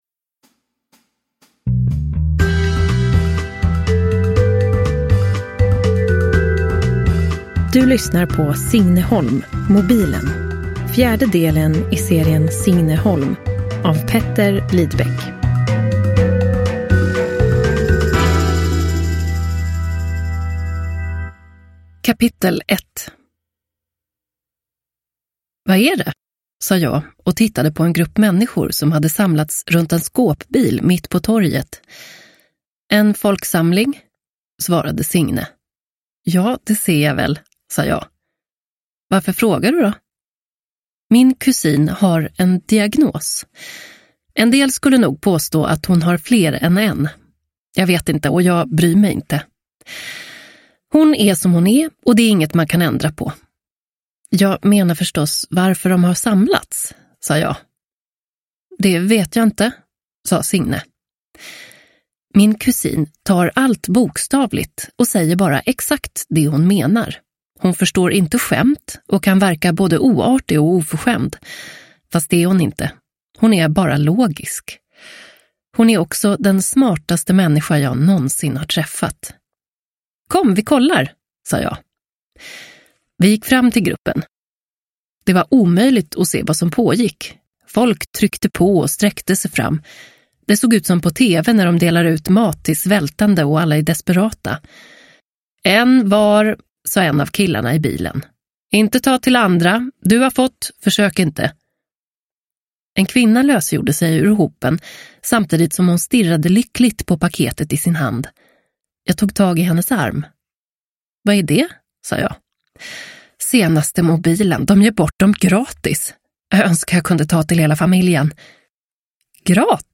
Mobilen – Ljudbok – Laddas ner